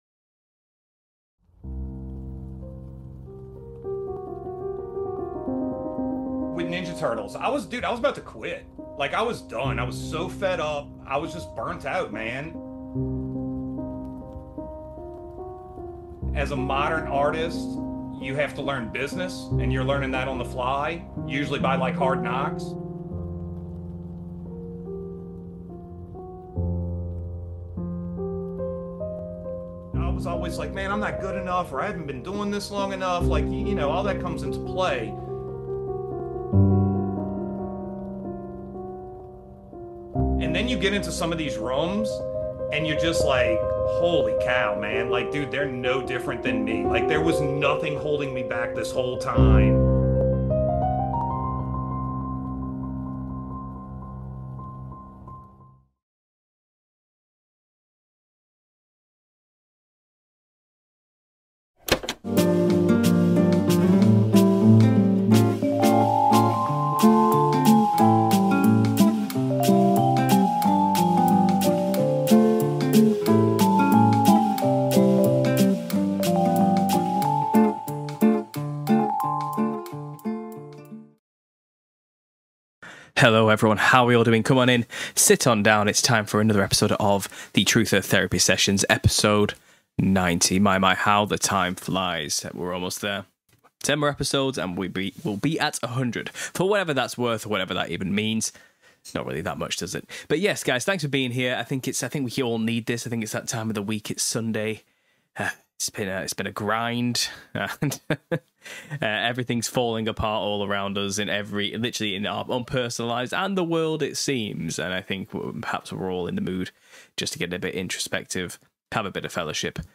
Live Q&A - 57 - The Lines In The Sky, Malfunctions & Robot Fashion – Understanding Conspiracy – Podcast